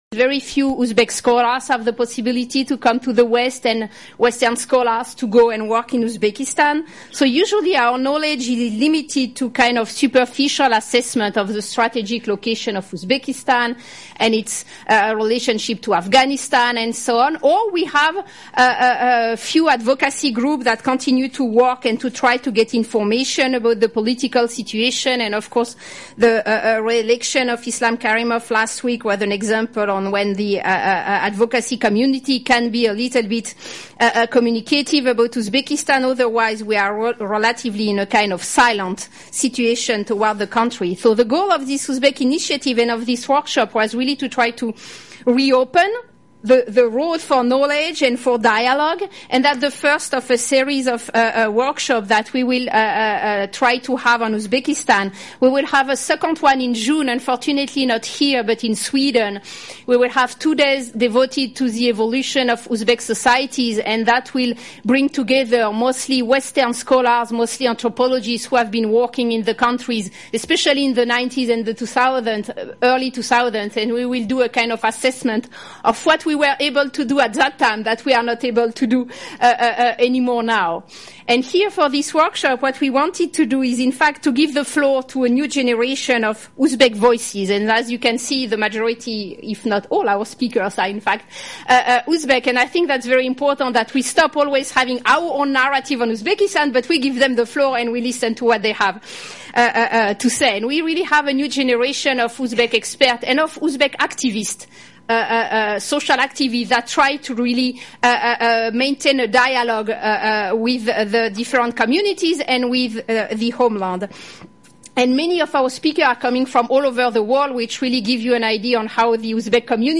The First Uzbekistan Initiative Workshop, Central Asia Program, GWU, April 6, 2015 - Session 1